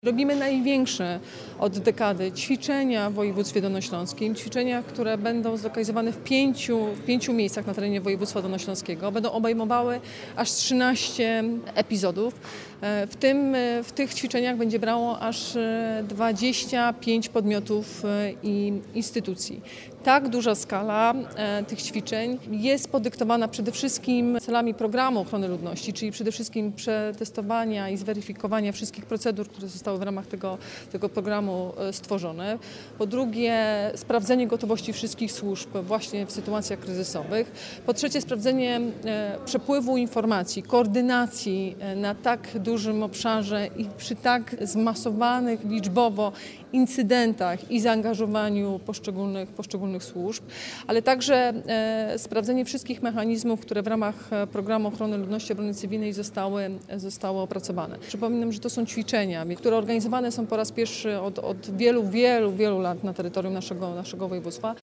Ćwiczenia są elementem Programu Ochrony Ludności i Obrony Cywilnej i mają zweryfikować, czy system działa w praktyce. Mówi Anna Żabska, wojewoda dolnośląska.